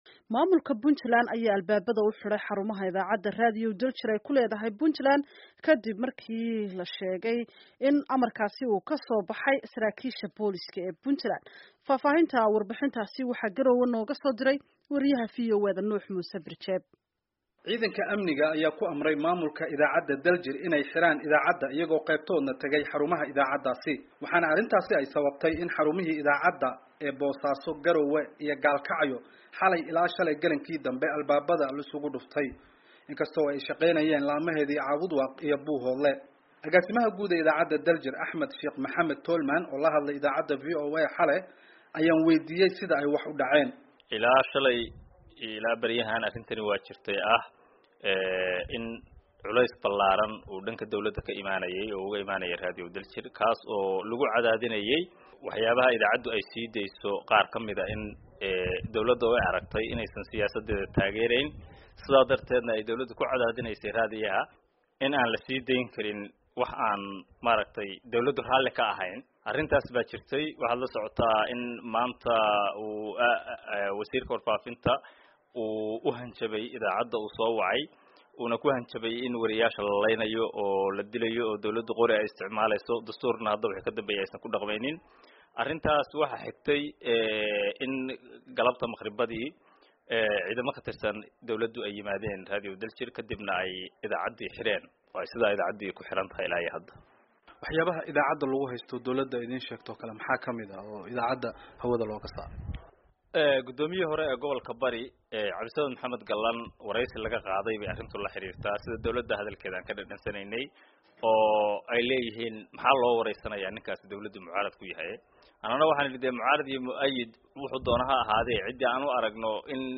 Warbixintan waxa magaalada Garoowe ka soo direy